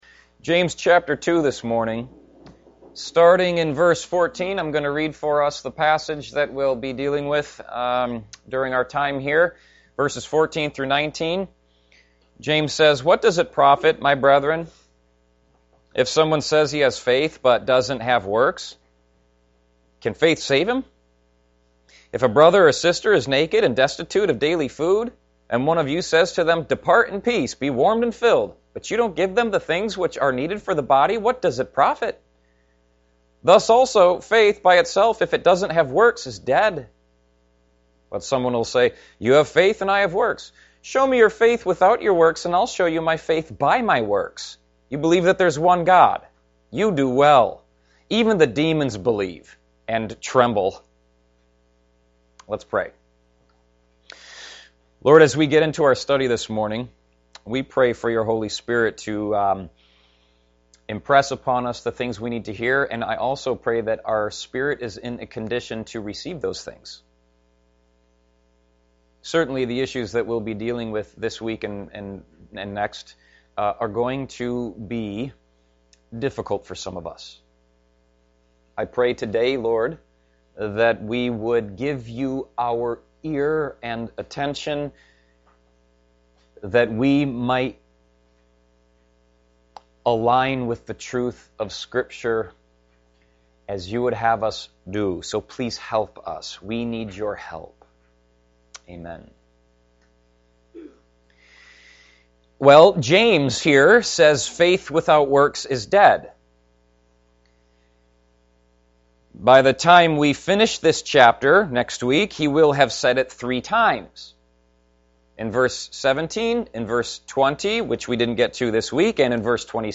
Recommended Audio Sermons for October